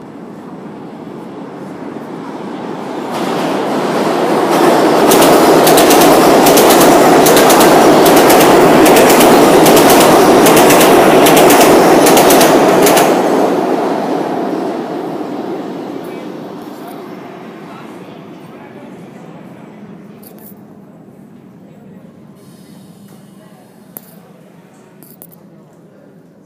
Field Recording 8
subway Passing subway train in NYC,
subway1.mp3